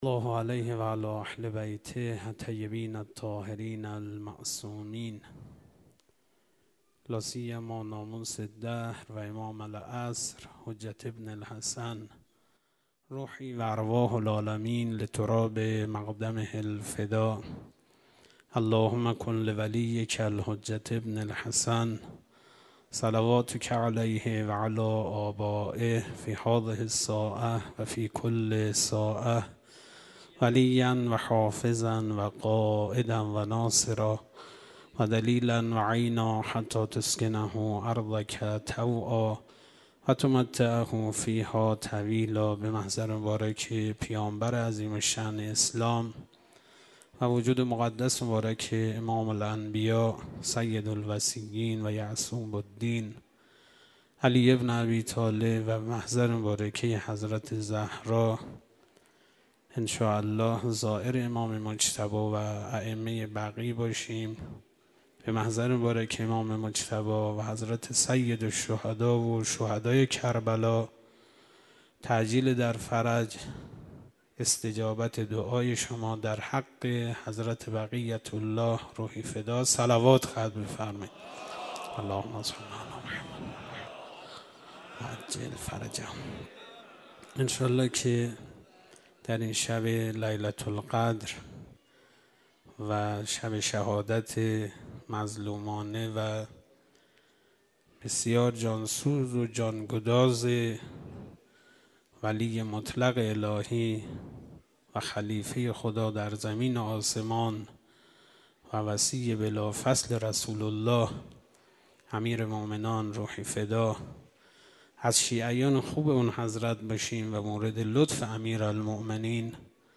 فایل های صوتی مراسم شب های قدر رمضان ۱۴۰۰